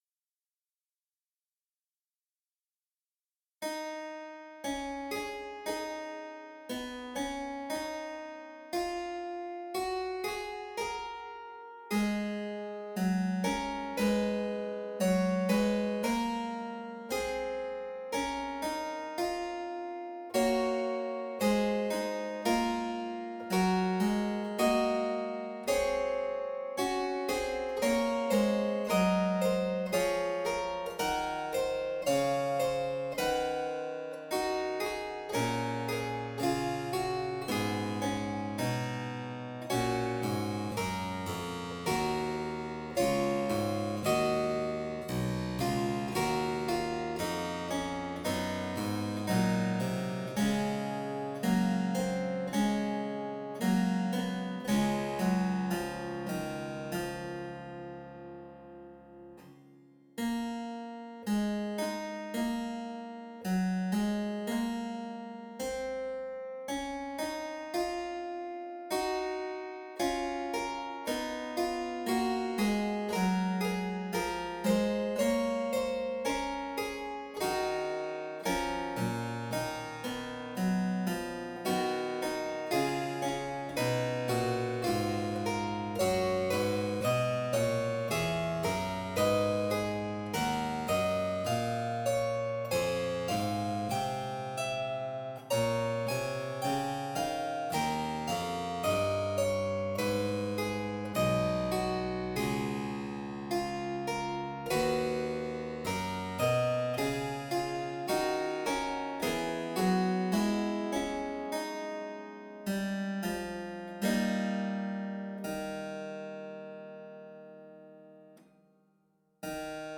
harpsichord Duration